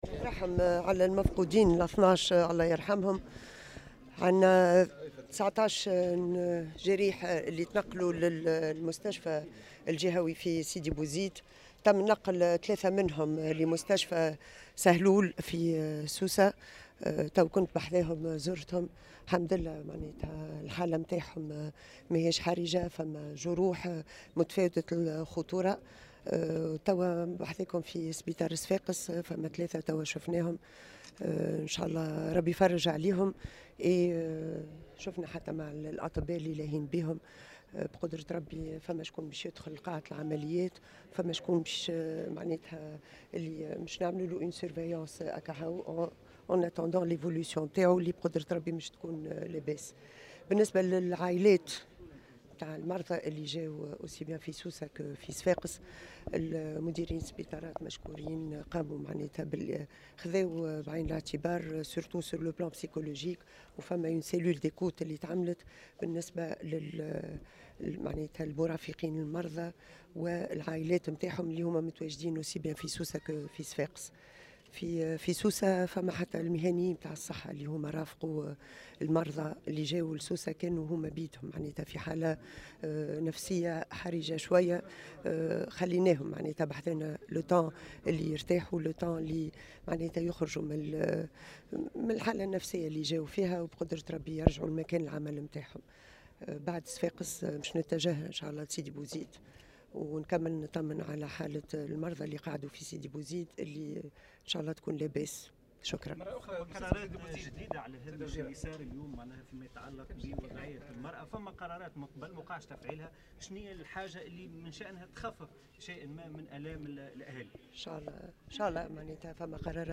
واضافت أن المجلس الوزاري الأخير كان تطرّق إلى هذه المسألة وسيتم تنفيذ القرارات، وفق تعبيرها في تصريح لمراسل "الجوهرة اف أم" على هامش زيارة أدتها إلى مستشفى صفاقس أين يقيم 3 جرحى ضحايا فاجعة السبالة بسيدي بوزيد والتي أسفرت عن وفاة 12 شخصا في انقلاب شاحنة لنقل عاملات الفلاحة.